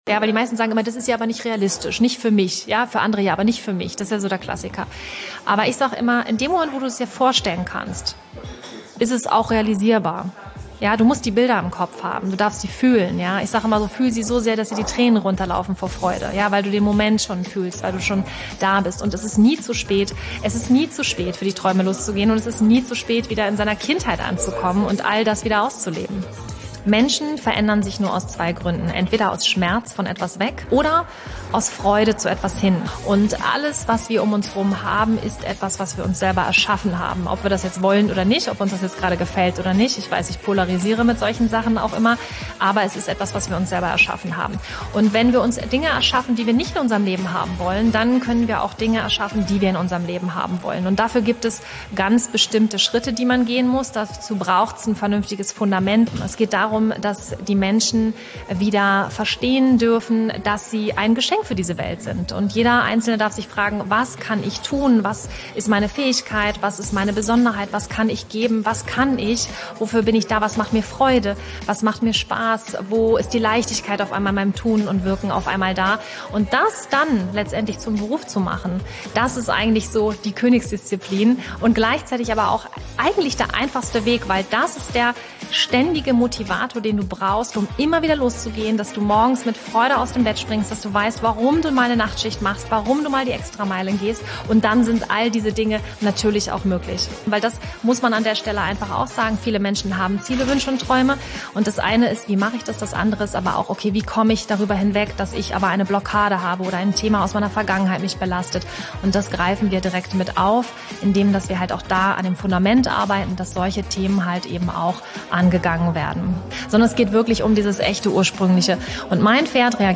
Ich war zu Gast bei AIDA-Radio.